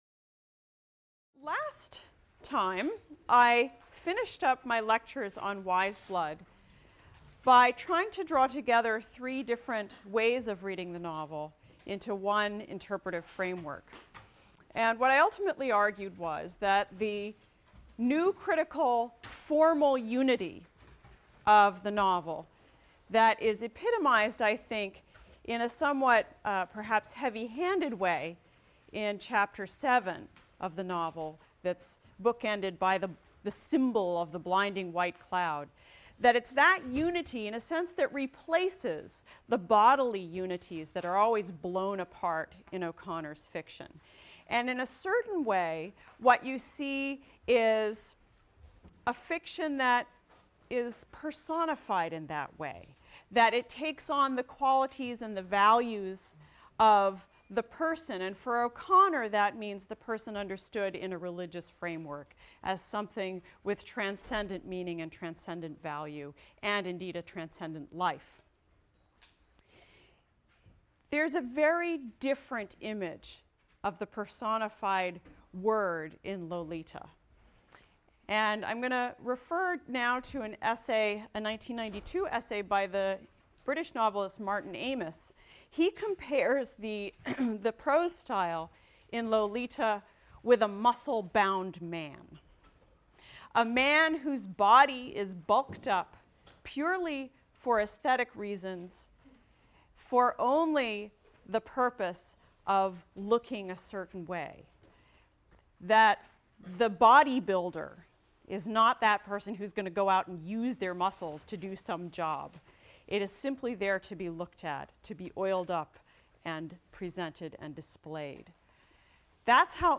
ENGL 291 - Lecture 5 - Vladimir Nabokov, Lolita | Open Yale Courses